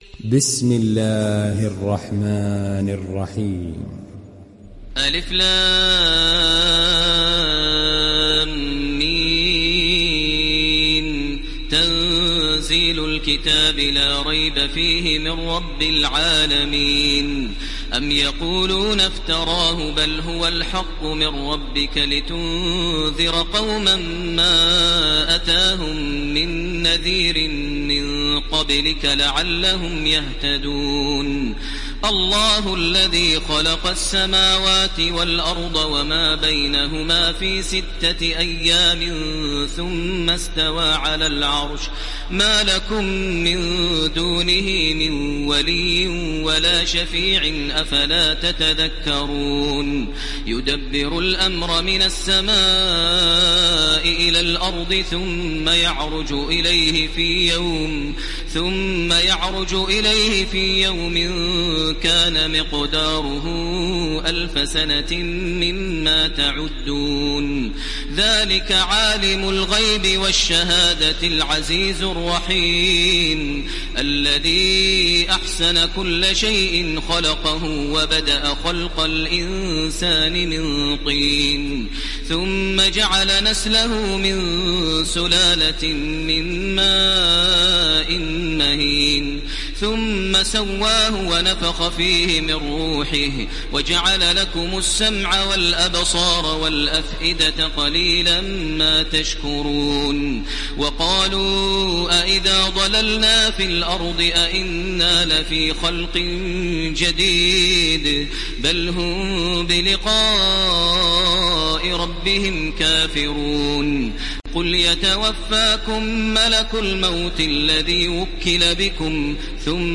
دانلود سوره السجده mp3 تراويح الحرم المكي 1430 (روایت حفص)
دانلود سوره السجده تراويح الحرم المكي 1430